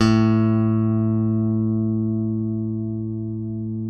ALEM PICK A2.wav